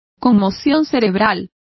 Complete with pronunciation of the translation of concussions.